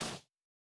Bare Step Snow Hard C.wav